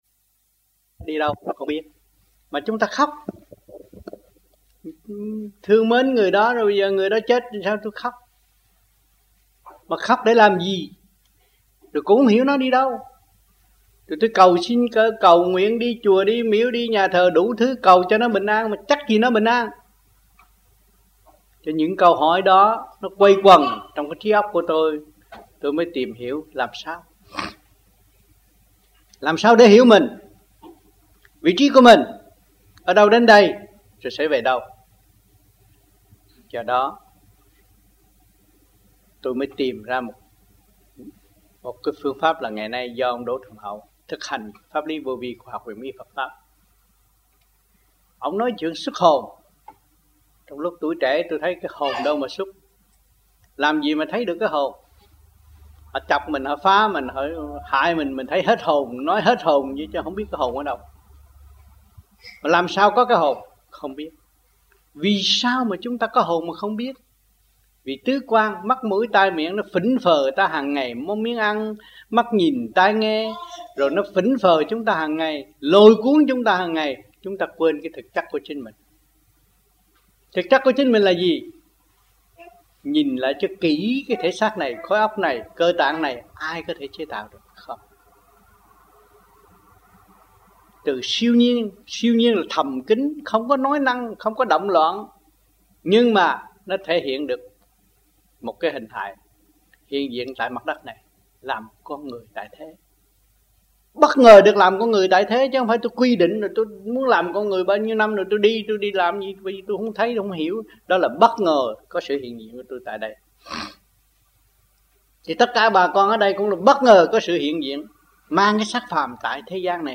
1990-05-06 - PERTH - LUẬN ĐẠO 3